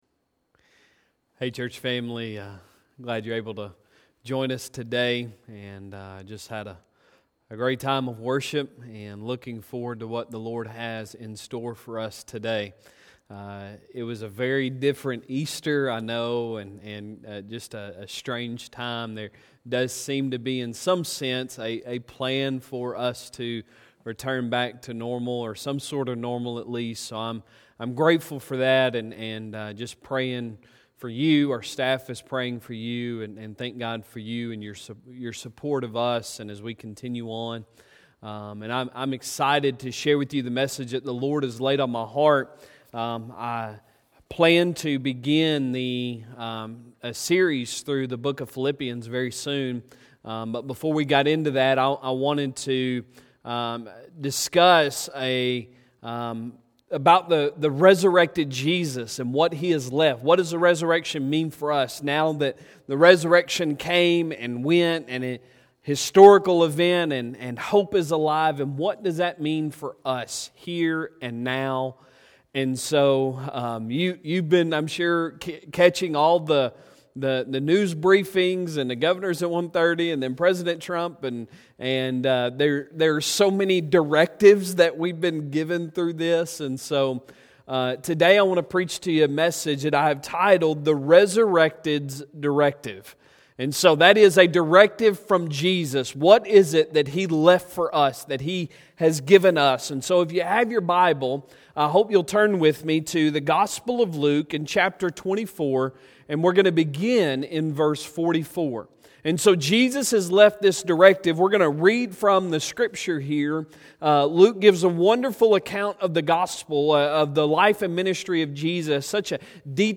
Sunday Sermon April 19, 2020